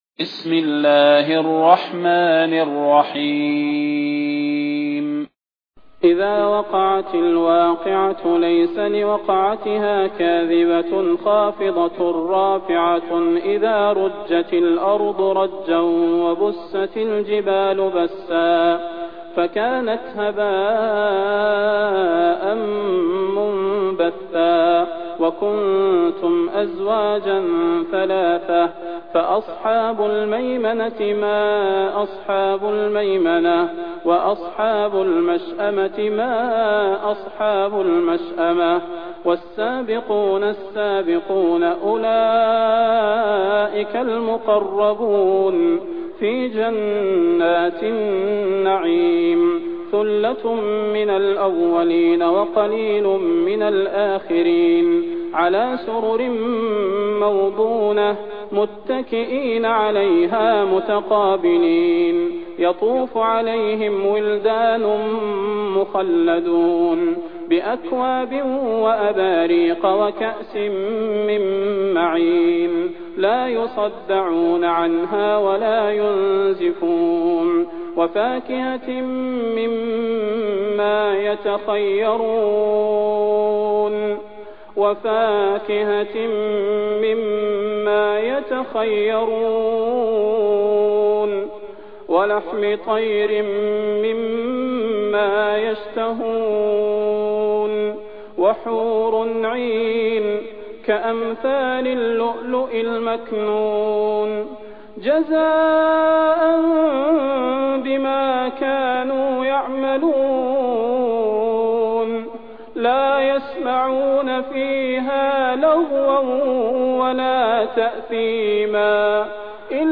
المكان: المسجد النبوي الشيخ: فضيلة الشيخ د. صلاح بن محمد البدير فضيلة الشيخ د. صلاح بن محمد البدير الواقعة